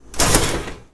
Open Oven Door Sound
household
Open Oven Door